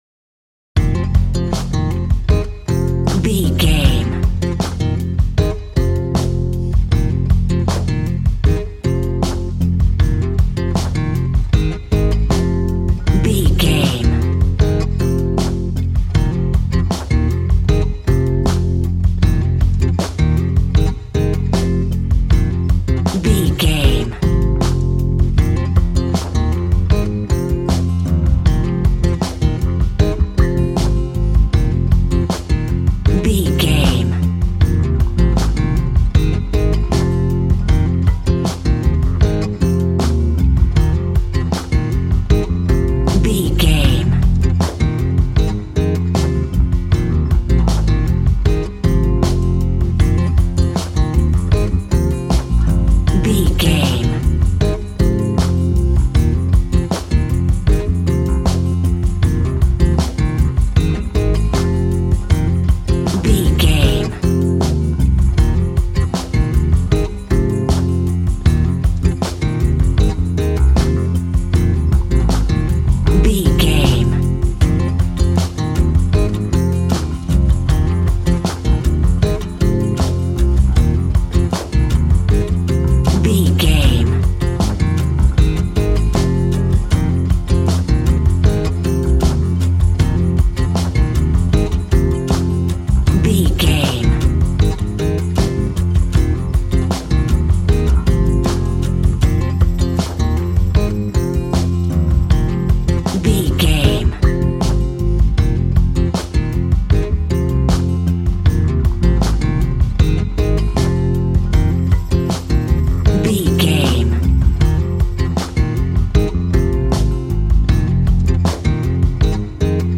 Aeolian/Minor
romantic
happy
acoustic guitar
bass guitar
drums